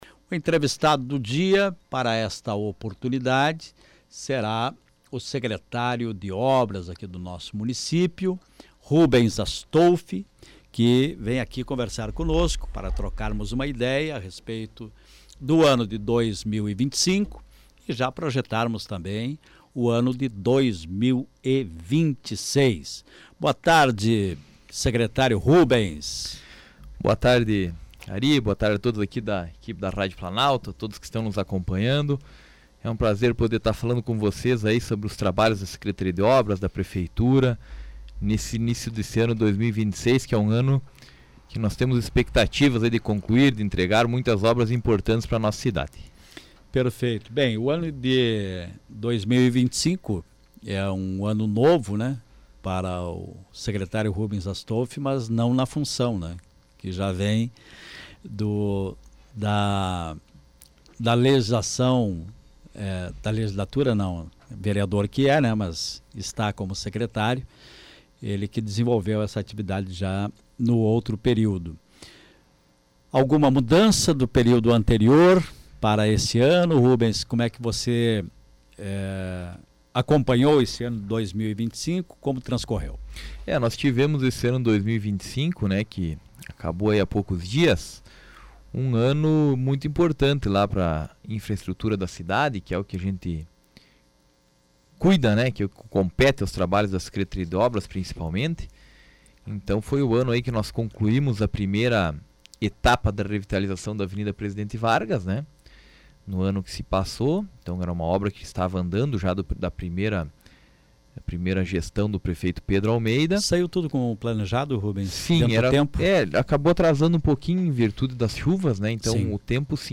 O secretário municipal de Obras de Passo Fundo foi o entrevistado do programa A Primeira Hora desta segunda-feira, 12, na Rádio Planalto News (92.1).